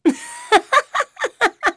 Aselica-Vox_Happy2.wav